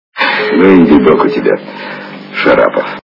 При прослушивании Приключения капитана Врунгеля - Ну и рожа у тебя Шарапов качество понижено и присутствуют гудки.